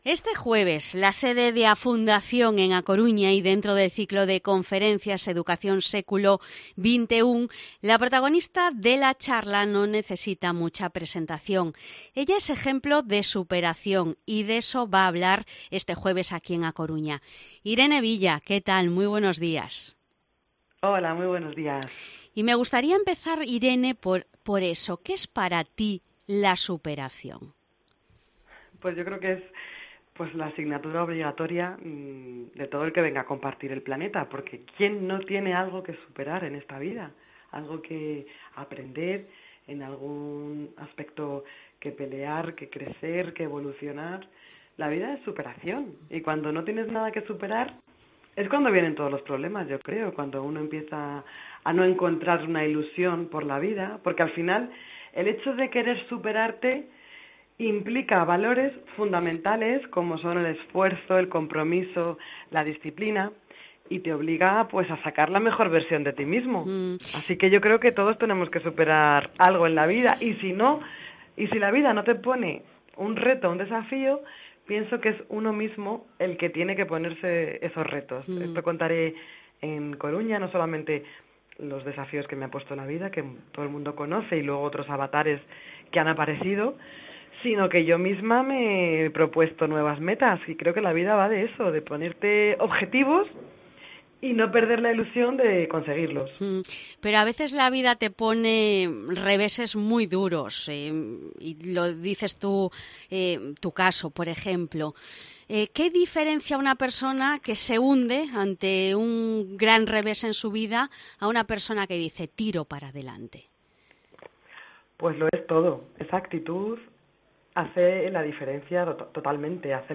Entrevista Irene Villa